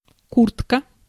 Ääntäminen
Ääntäminen France: IPA: [bluzɔ̃] Haettu sana löytyi näillä lähdekielillä: ranska Käännös Ääninäyte Substantiivit 1. куртка {f} (kurtka) Suku: m .